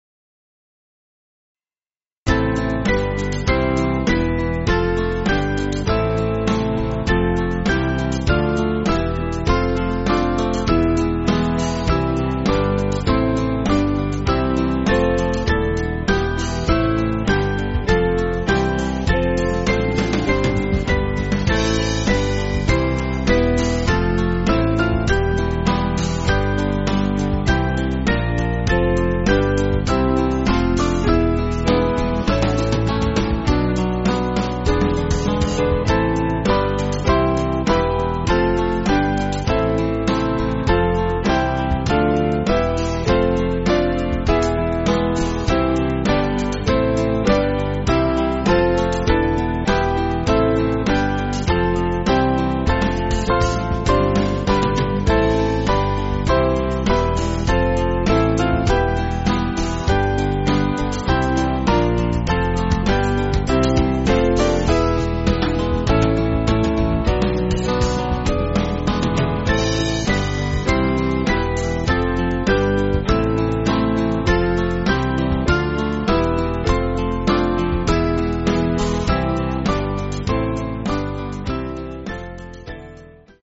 Small Band
(CM)   5/Am